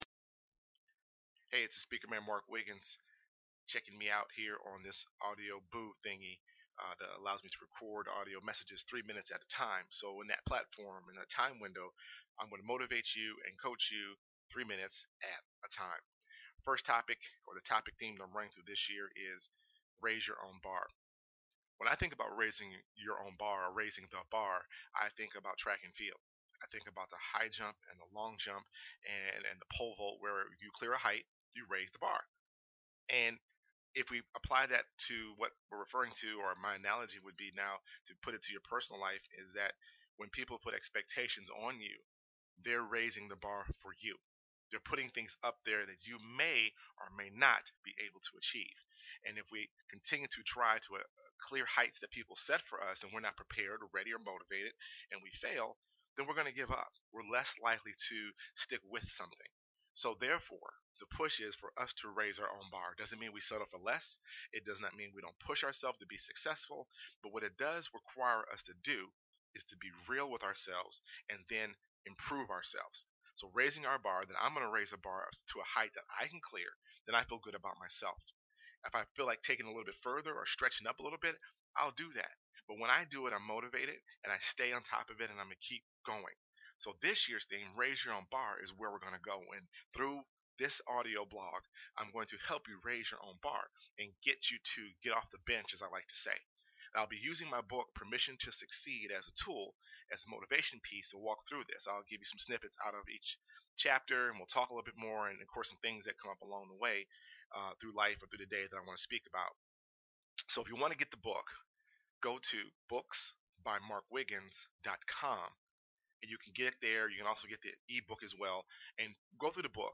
"Raise your own Bar" motivational message